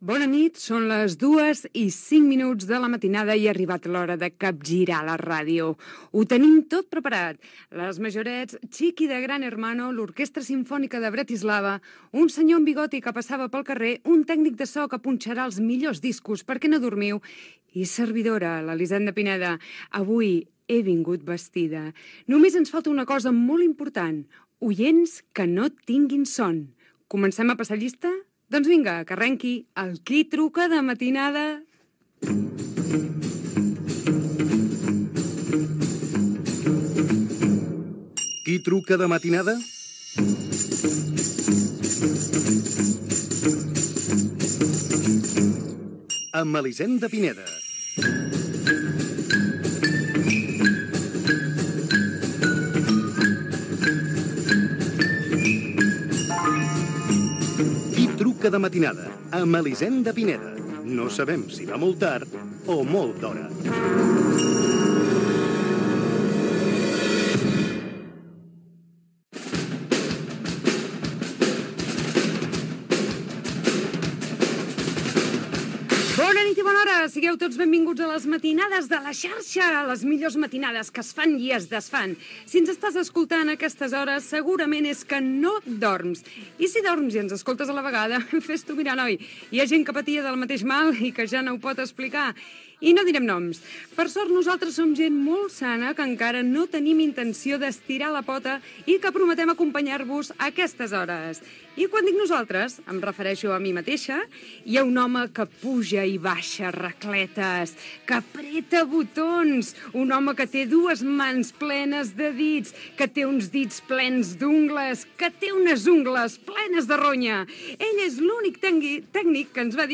Presentació, careta, diàleg amb el tècnic de so, tutulars de premsa